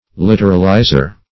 Search Result for " literalizer" : The Collaborative International Dictionary of English v.0.48: Literalizer \Lit"er*al*i`zer\ (l[i^]t"[~e]r*al*[imac]*z[~e]r), n. A literalist.
literalizer.mp3